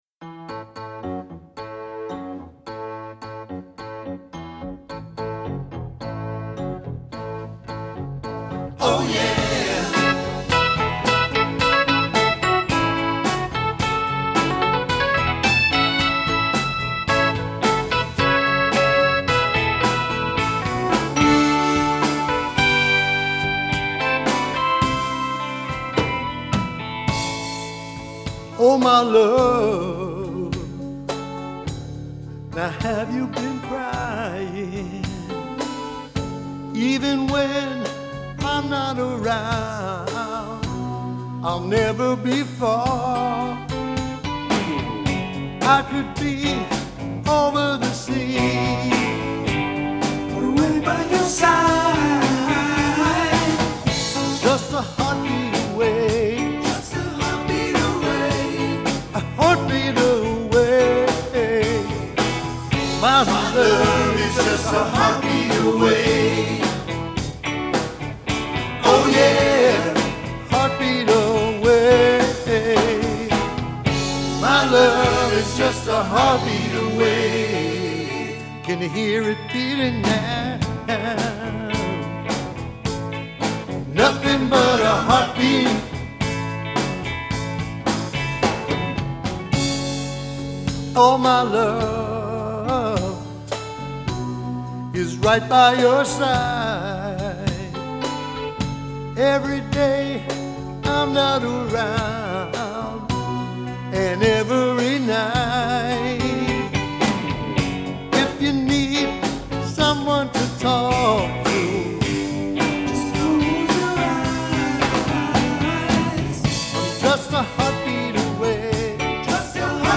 The track is smacking with Nitro. I don't think I've ever been involved in a track that has that much punch.
We have two takes of each and I'm not sure which will make it and which won't. On a quick listen over cans, it seems like they are all ok and in fact playing them together sounds pretty spectacular.
We basically want our recordings to sound like the band sounds live.
We used Audix OM5 mics up close with the right ToneMatch setting.
Same organic, molecular mixing we got with the instruments (thus complete sonic compatibility with the same) and a great blend that we all heard and corrected easily.